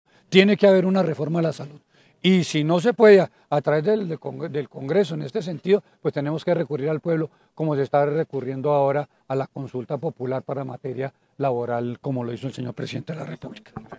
Guillermo Alfonso Jaramillo, Ministro de Salud
El Ministro de Salud, Guillermo Alfonso Jaramillo, dentro del desarrollo de la primera audiencia pública descentralizada sobre la reforma a la salud, que se realizó en Bucaramanga, no descartó la posibilidad de recurrir a una consulta popular, en caso de que en el congreso la reforma a la salud, presente el mismo panorama que tuvo la laboral.